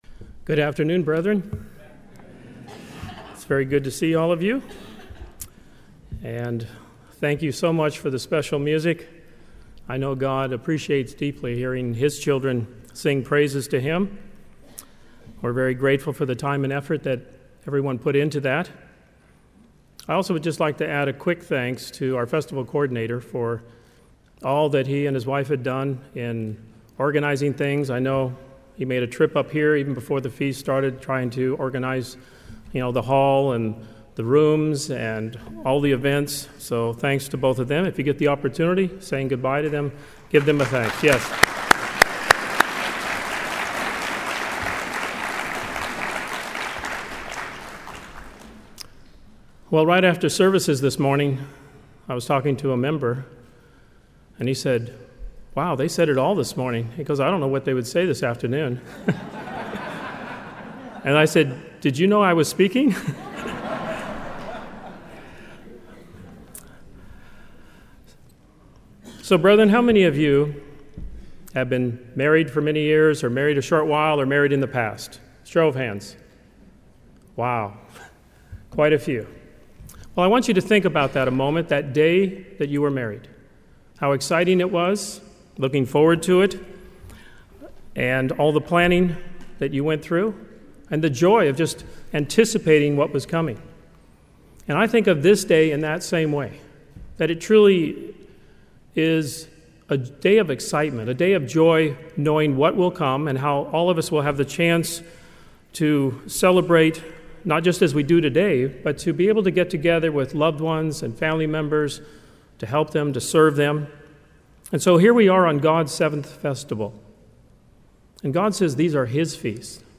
This sermon was given at the Jekyll Island, Georgia 2019 Feast site.